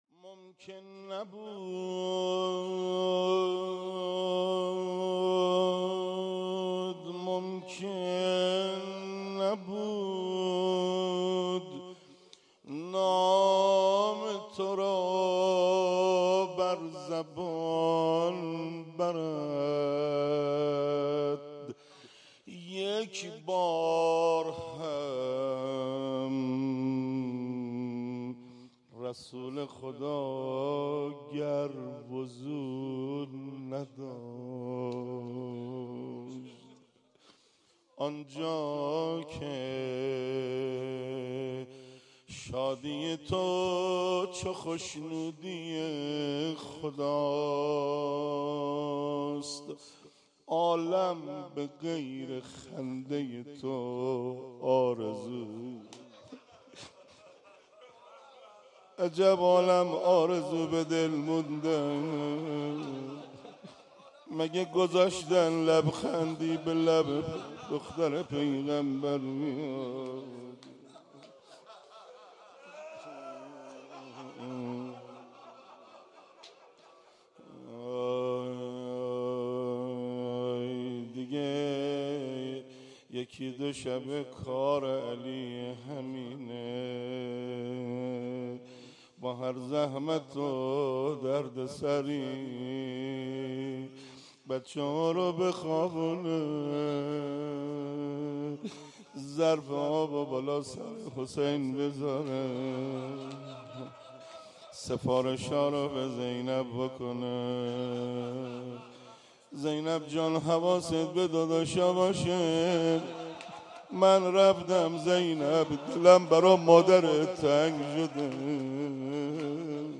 مناسبت : شهادت حضرت فاطمه زهرا سلام‌الله‌علیها
قالب : روضه